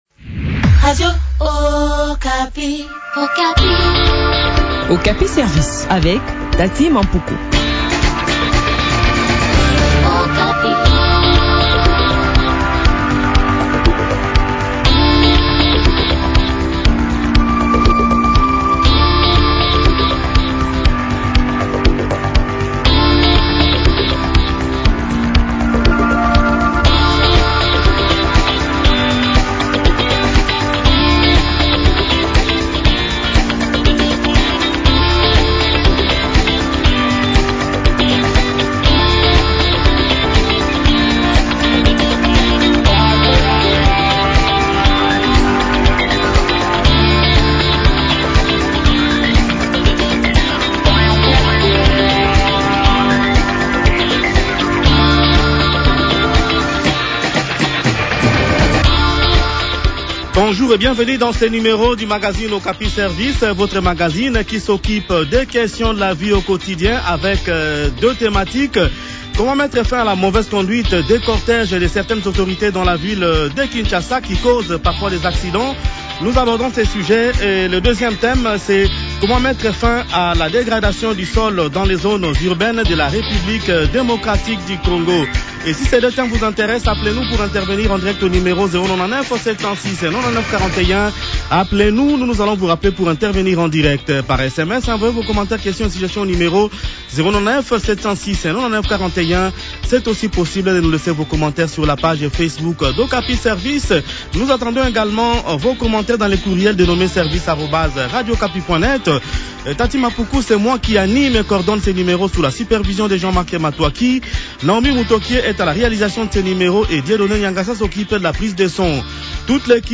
a également pris part à cette interview.